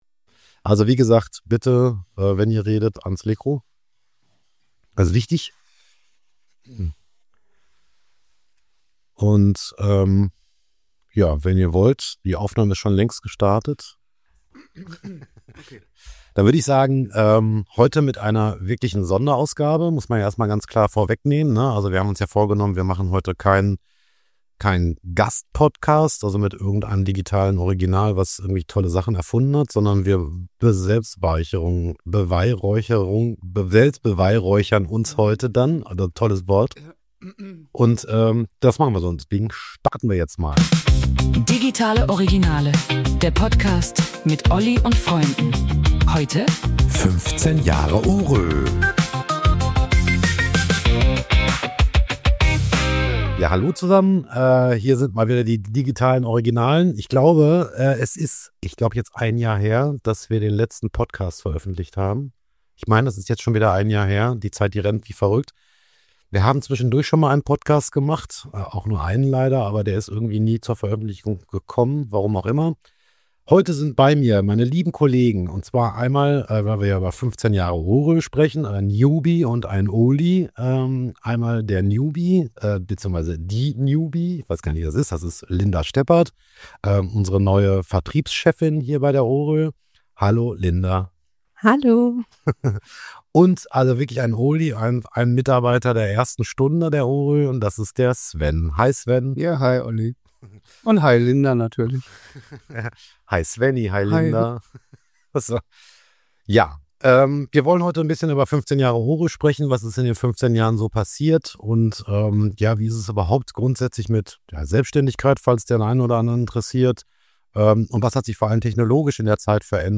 Wir lassen die Technologien, die vor 15 Jahren en vogue waren, Revue passieren und sprechen über die Veränderungen, mit denen wir und die gesamte Branche durch KI konfrontiert sind. Es war ein lockerer und spontaner interner Austausch.